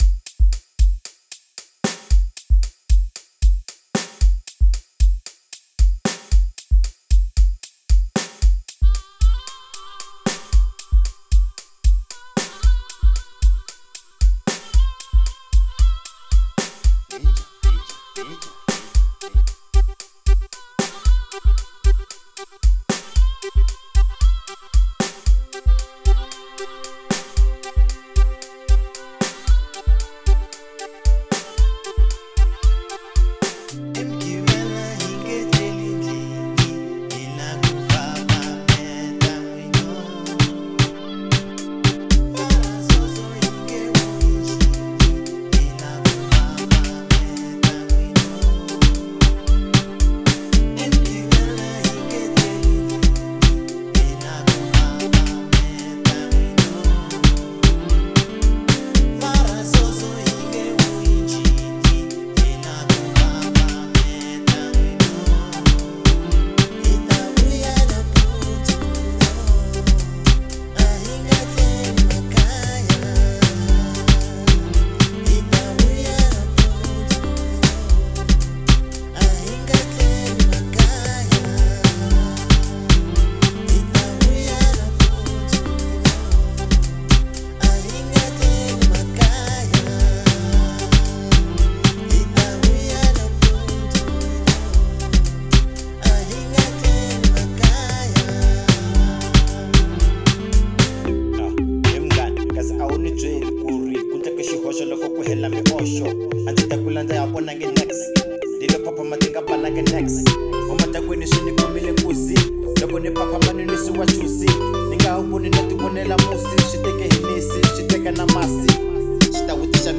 04:21 Genre : House Size